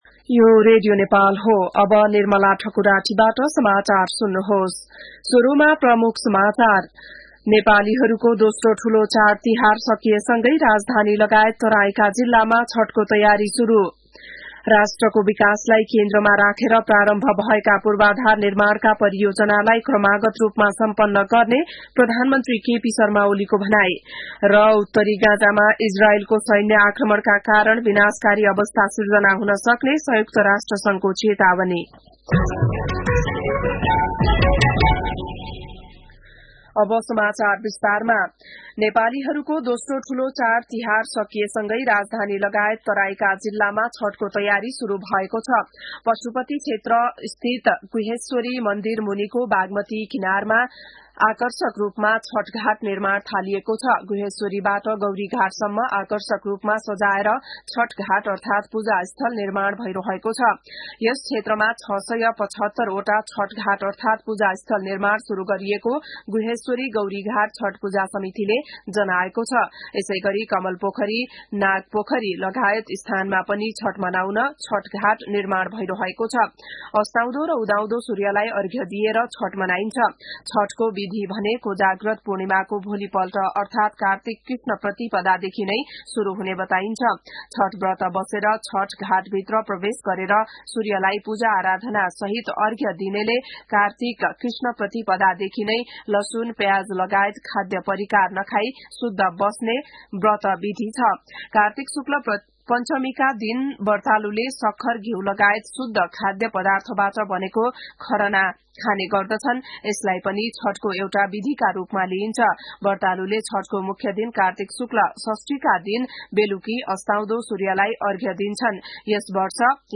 बिहान ९ बजेको नेपाली समाचार : २० कार्तिक , २०८१